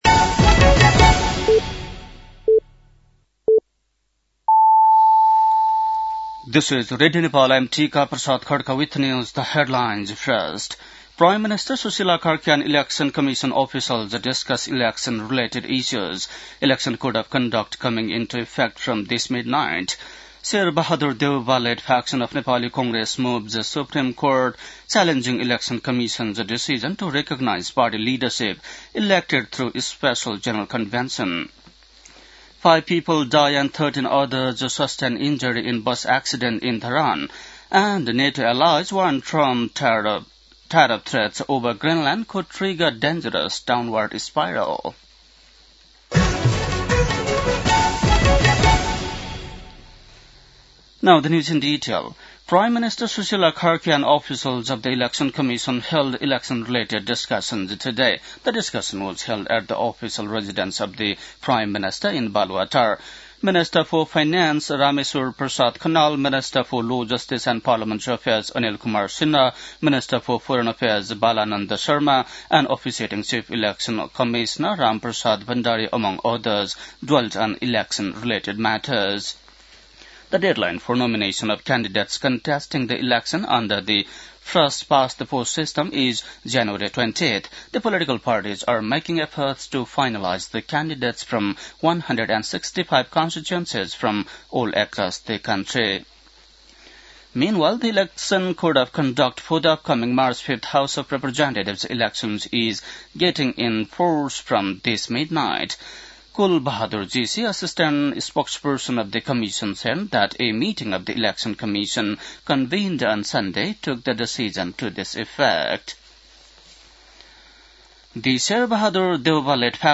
बेलुकी ८ बजेको अङ्ग्रेजी समाचार : ४ माघ , २०८२
8-pm-english-news-10-04.mp3